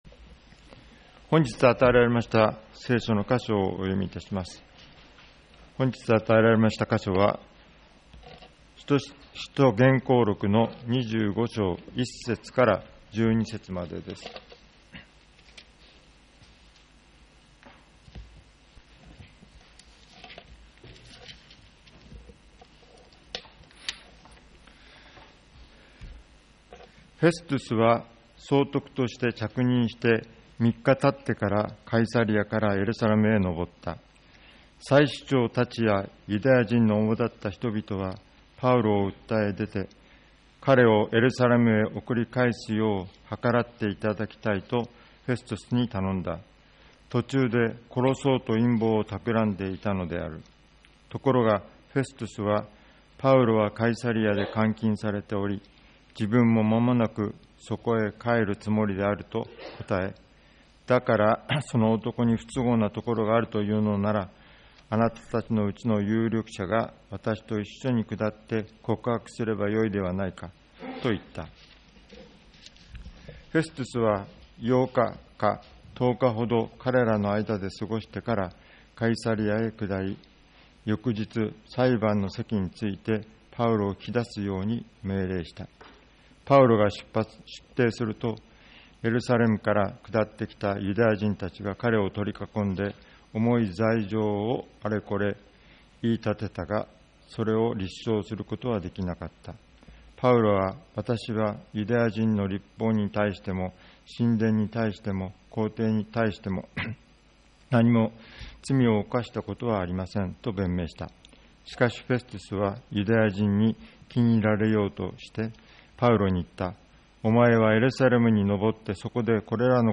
■ ■ ■ ■ ■ ■ ■ ■ ■ 2020年10月 10月4日 10月11日 10月18日 10月25日 毎週日曜日の礼拝で語られる説教（聖書の説き明かし）の要旨をUPしています。